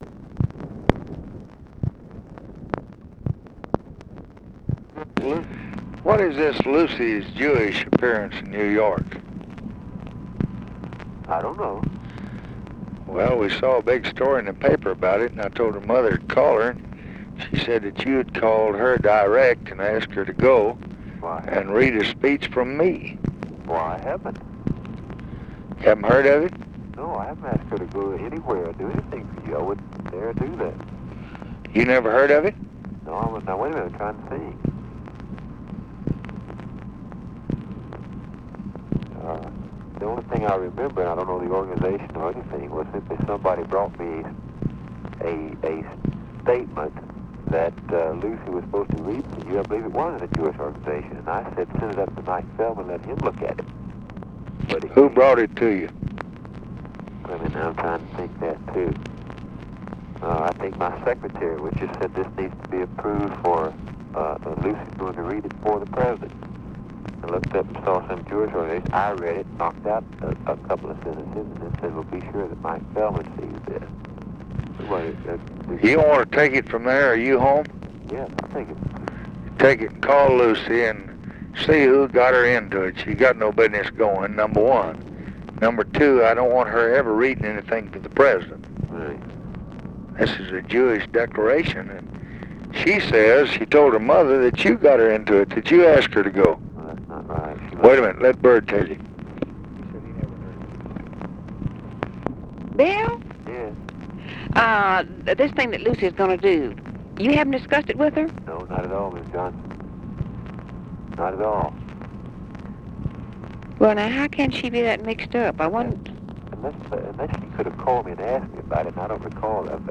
Conversation with BILL MOYERS and LADY BIRD JOHNSON, November 22, 1964
Secret White House Tapes